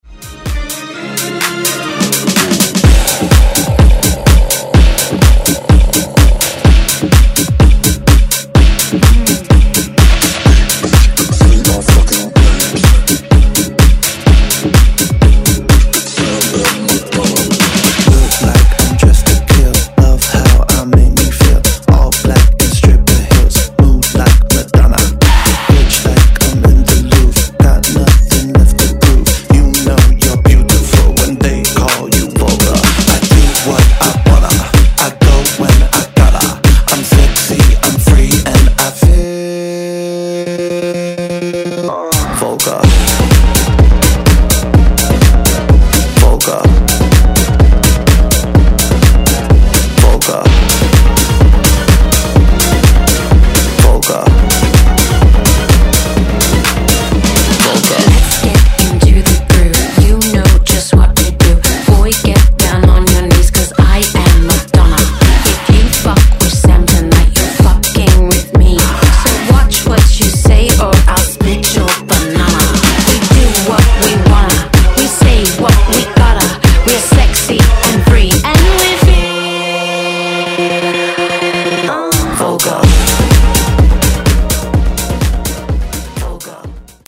Genre: R & B
Clean BPM: 92 Time